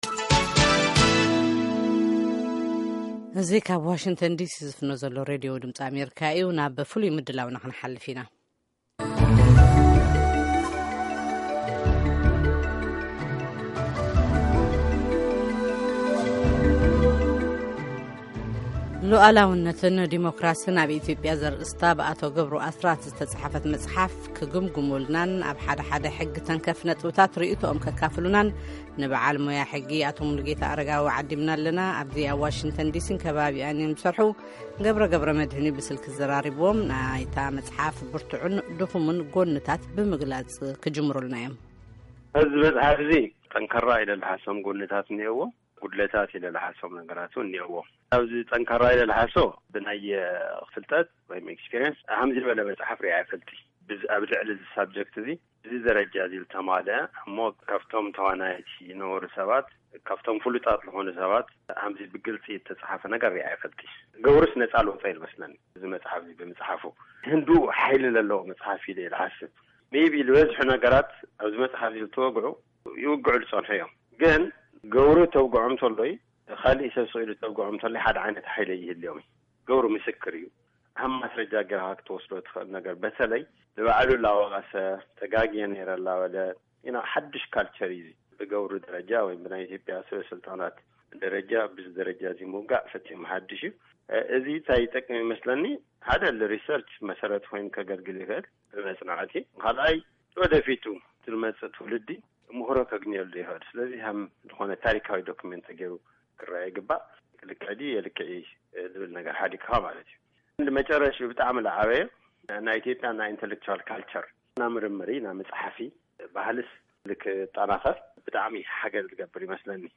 ቃለ-መጠይቕ